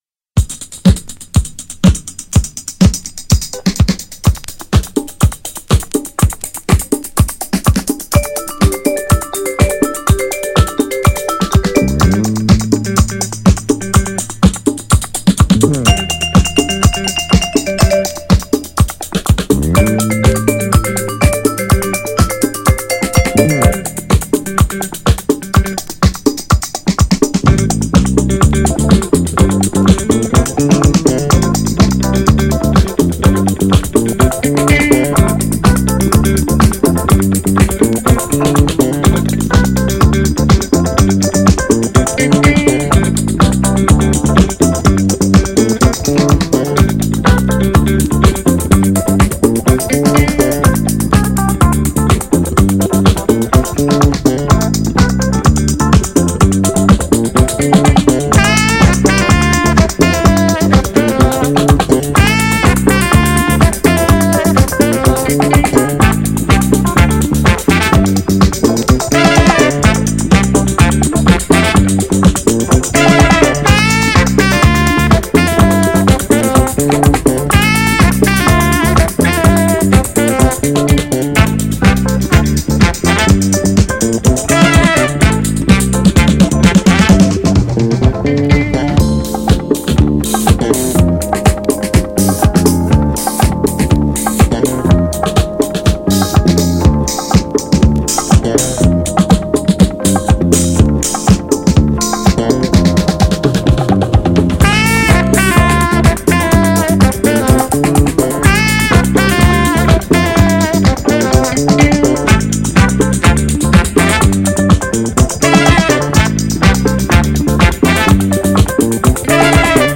GENRE Dance Classic
BPM 131〜135BPM